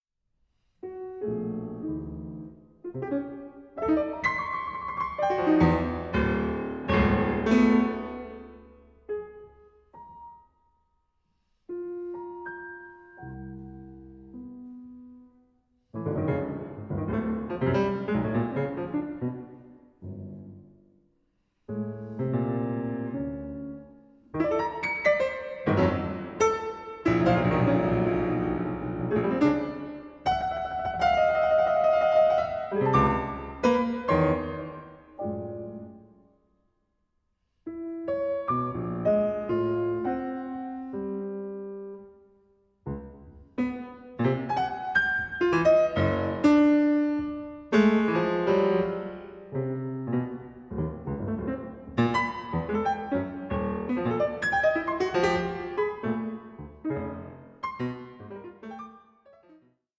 Vif 12:25